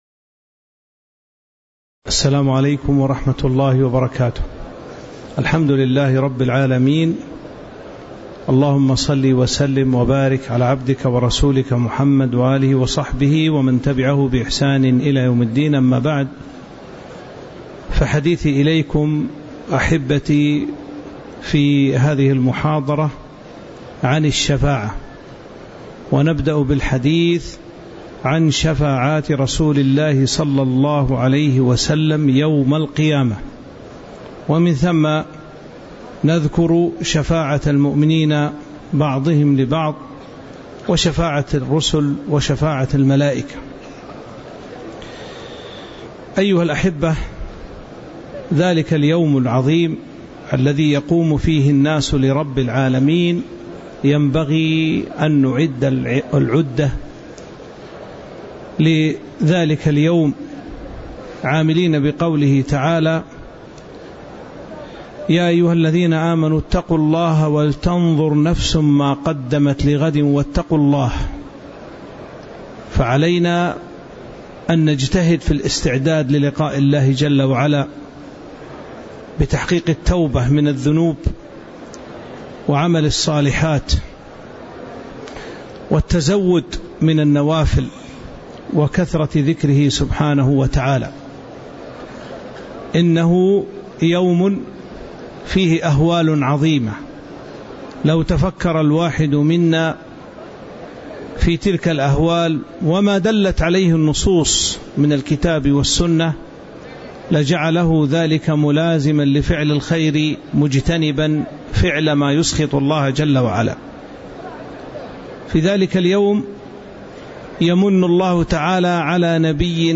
تاريخ النشر ١٨ ذو الحجة ١٤٤٥ هـ المكان: المسجد النبوي الشيخ